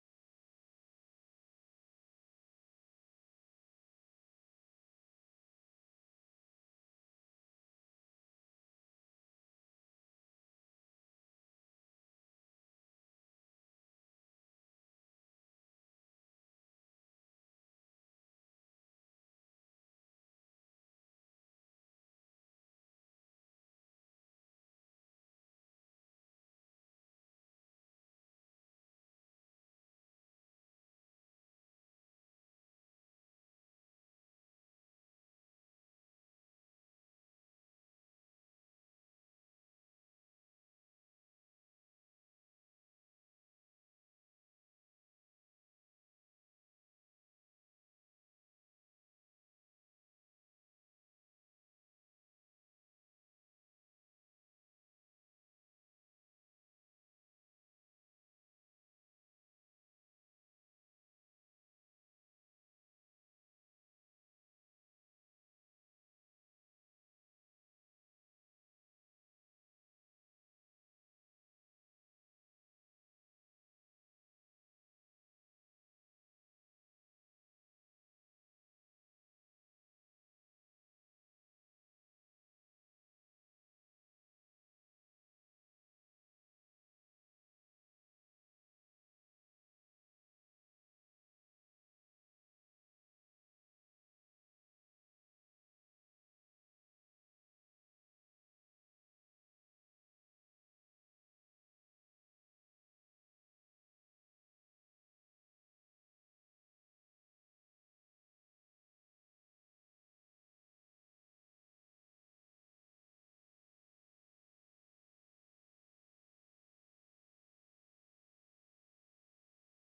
with the lead guitars removed